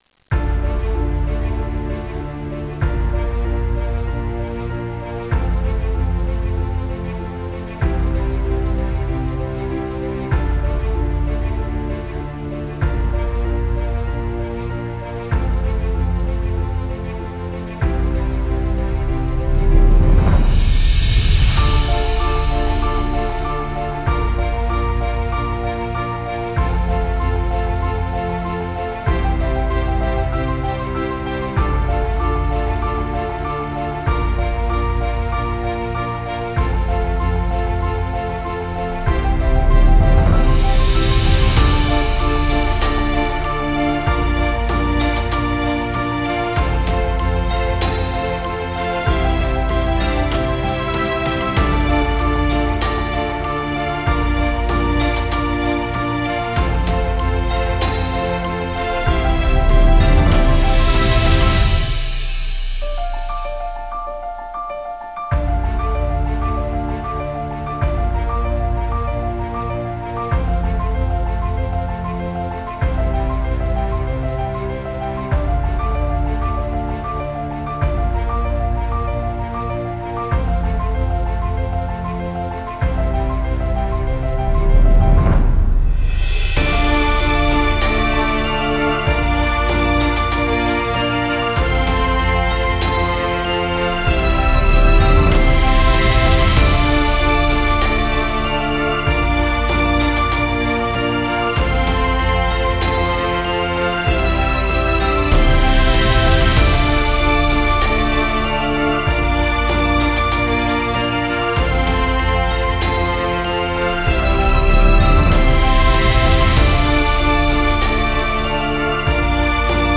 LCrs3vIz5LT_Inspiring-Dramatic-1-.wav